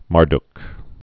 (märdk)